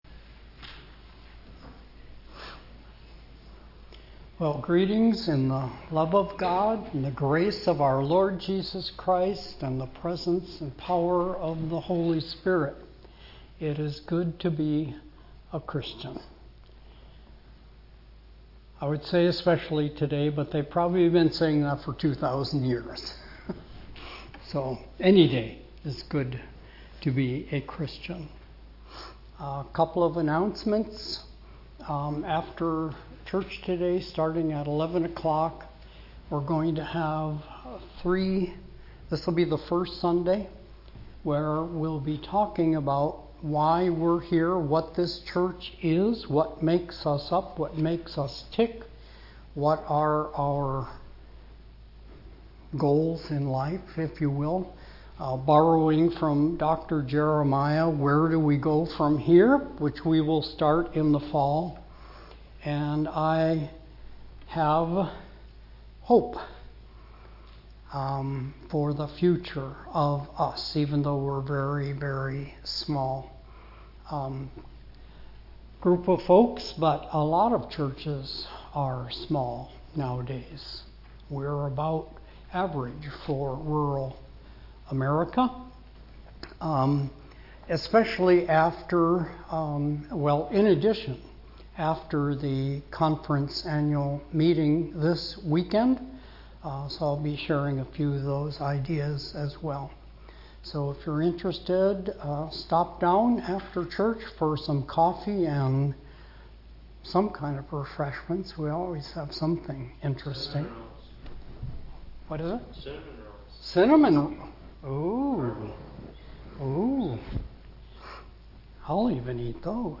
2025 Sermons